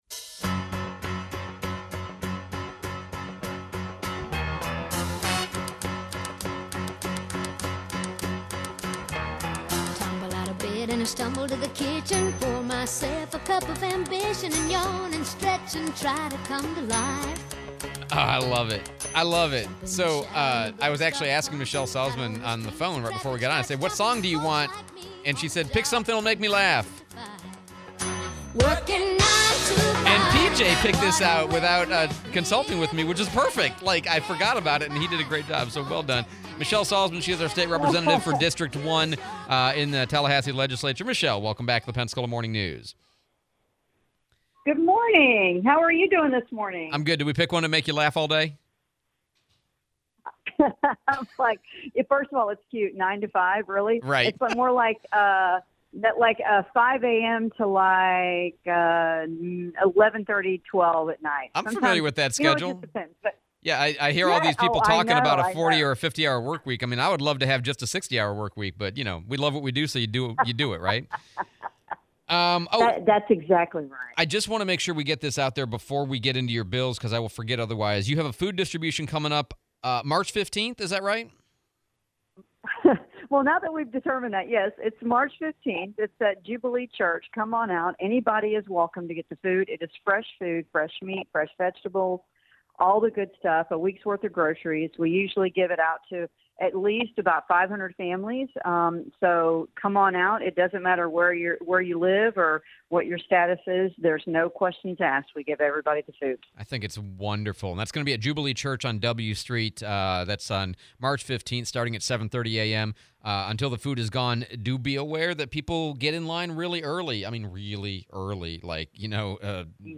02/27/25 Interview with Rep Salzman